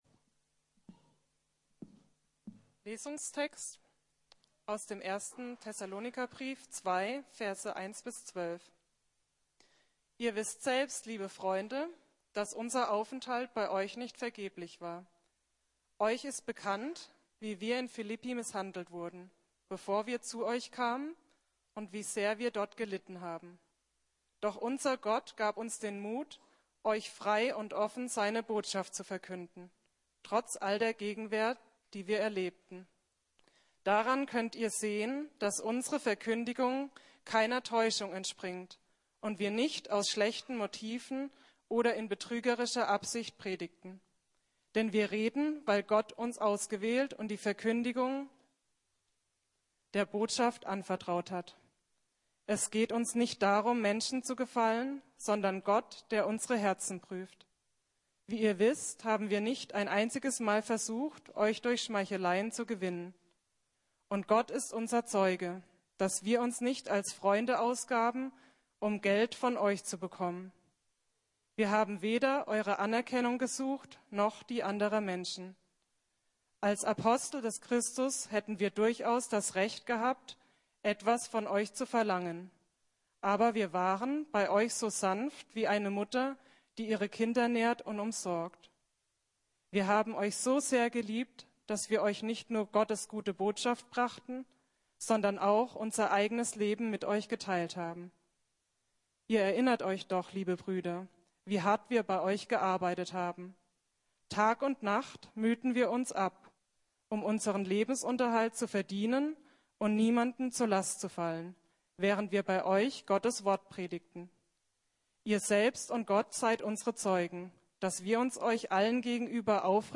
Authentische Liebe ~ Predigten der LUKAS GEMEINDE Podcast